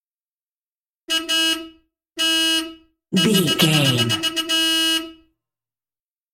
Car horn
Sound Effects